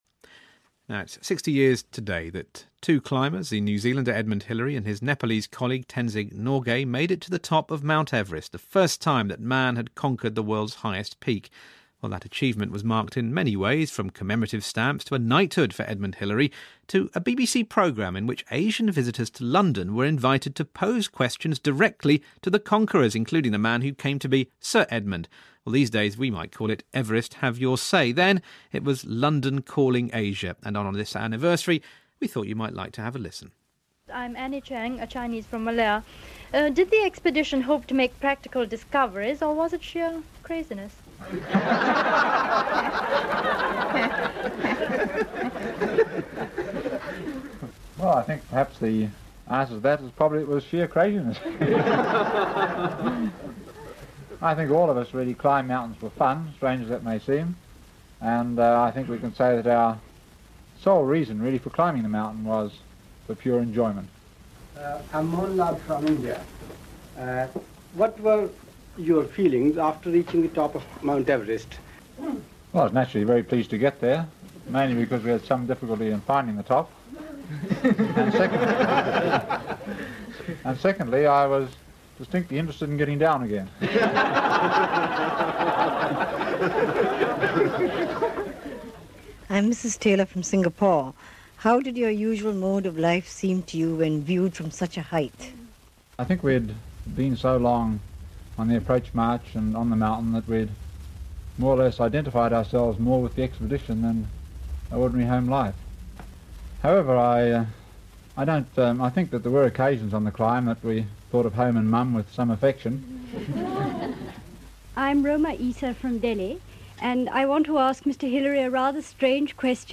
On his return to London, the man who became Sir Edmund answered questions from BBC listeners - here are some of them.